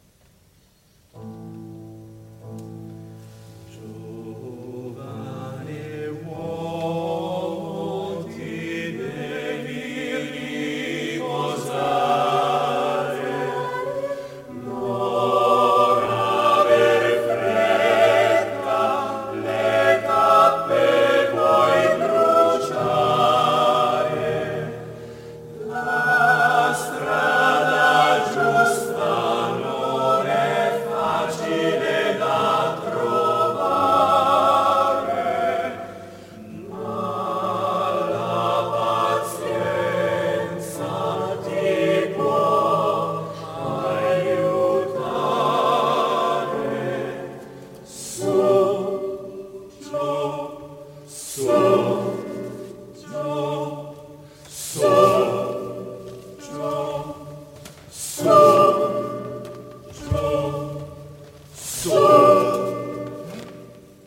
13 coro